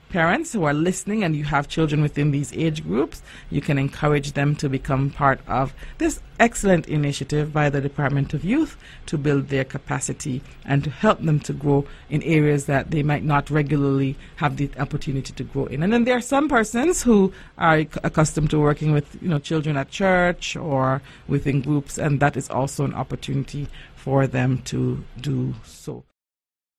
Ms. Claxton gave some encouragement: